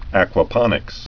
(ăkwə-pŏnĭks, äkwə-)